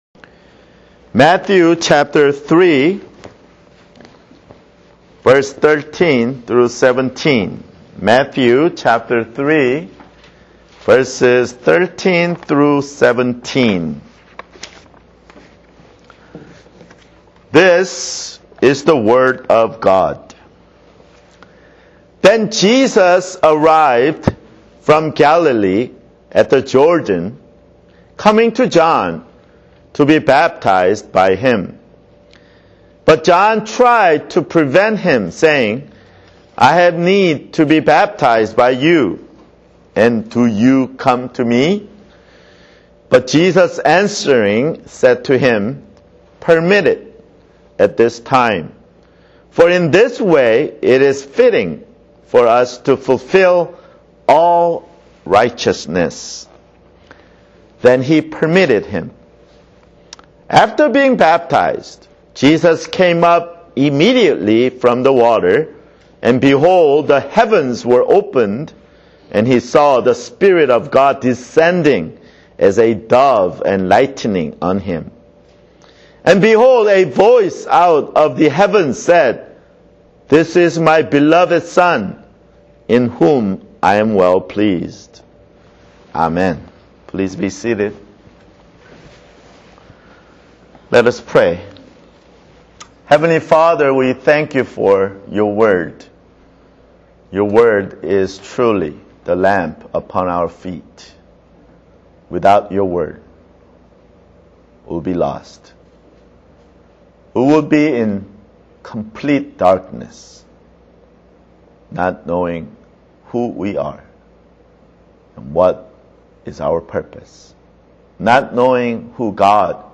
[Sermon] Matthew (8)